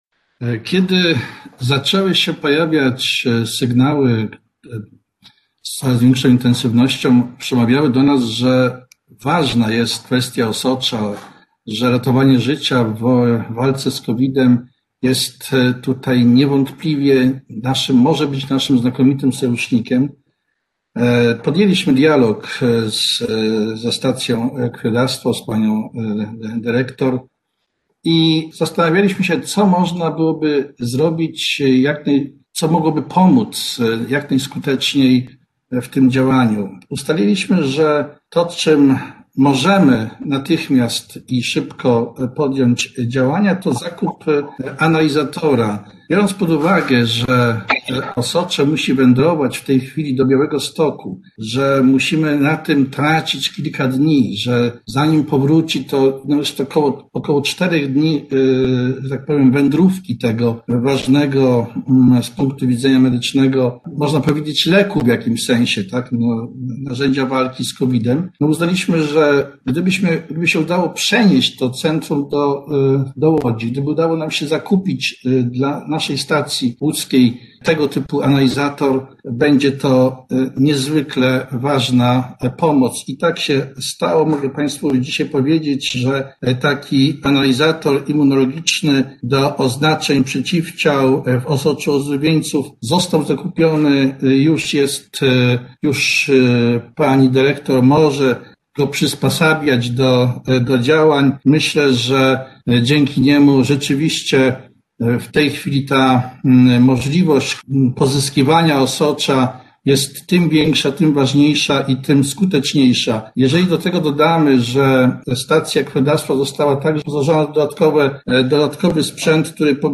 Grzegorz Schreiber podczas telekonferencji podkreślił, że Regionalne Centrum Krwiodawstwa i Krwiolecznictwa w Łodzi zostało także wyposażone w dwa dodatkowe separatory do pobierania osocza od dawców: Daje nam to kolejny dodatkowy element w walce z epidemią.